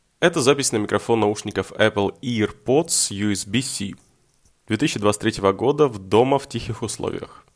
Микрофон отличный 10 из 10